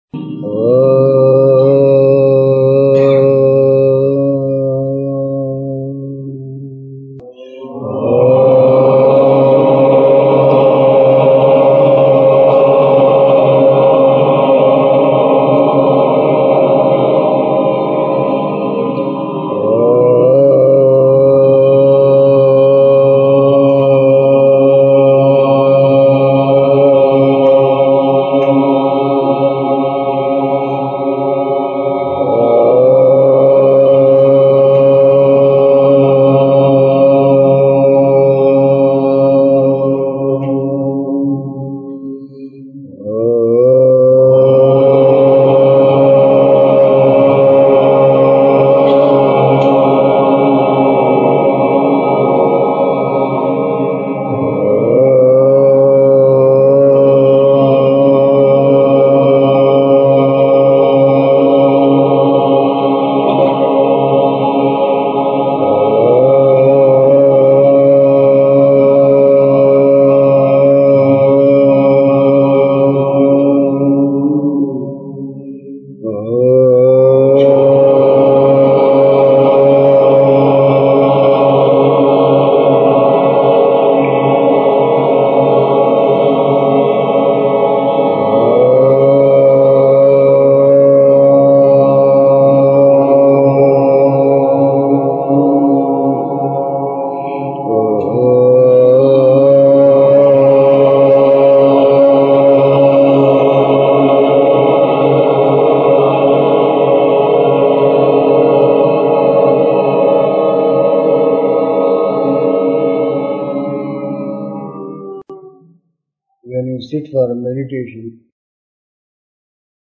[Sri Swami Krishnanandaji Maharaj leads the group in chanting Om.]